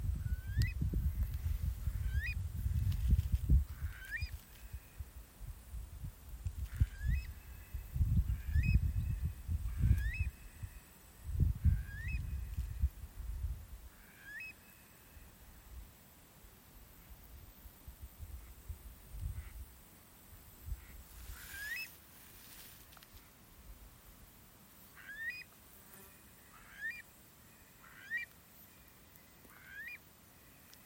Kuitala, Numenius arquata
Administratīvā teritorijaAlūksnes novads
StatussDzirdēta balss, saucieni